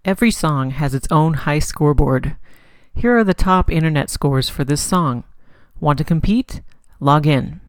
high score board popup.ogg